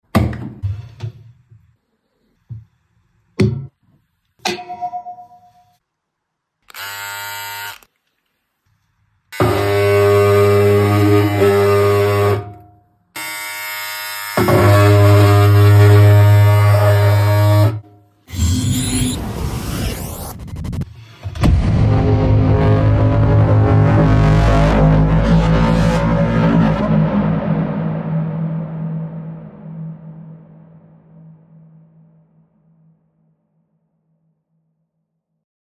What happens when you place an electric razor against a washing machine? Vibrations travel through metal, captured with a Geophone and contact mic, revealing hidden textures of sound. But the real magic happens in post-production-where raw mechanical noise transforms into a deep, cinematic trailer bender sound. 9⑦ From household vibrations to Hollywood tension.